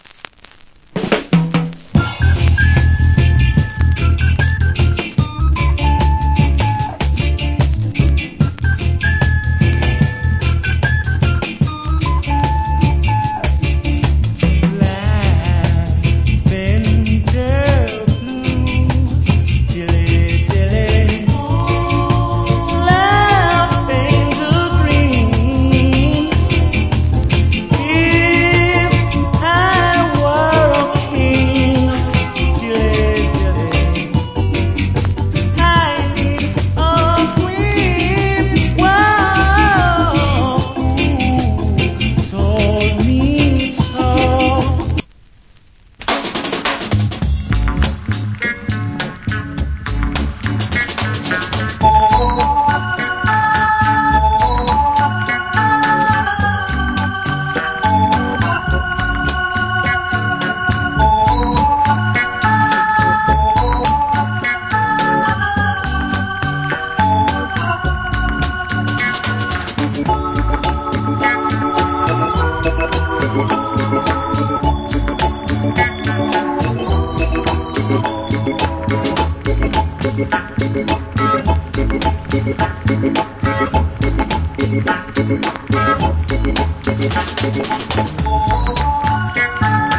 Great Early Reggae Vocal.